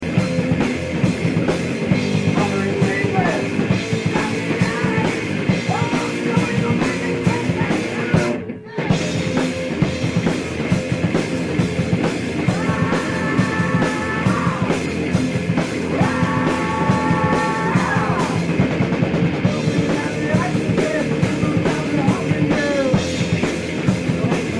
Avec une fin apocalyptique, tous part dans tous les sens.